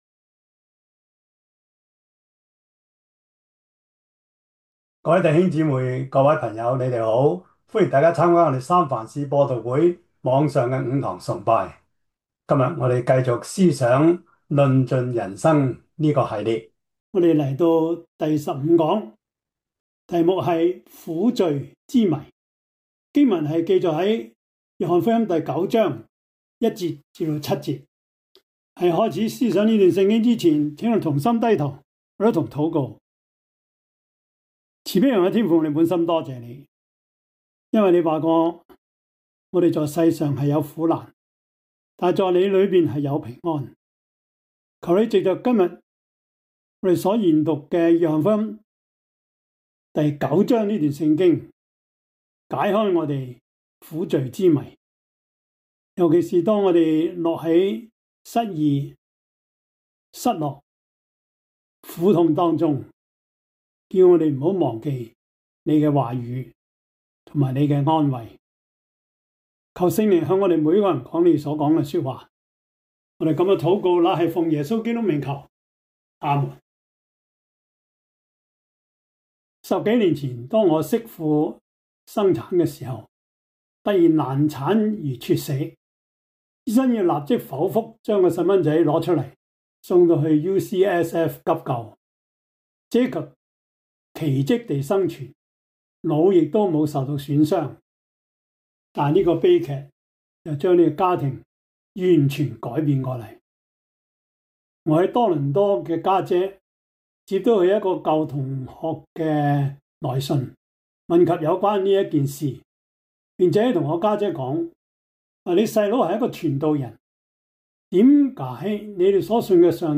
1-7 Service Type: 主日崇拜 約翰福音 9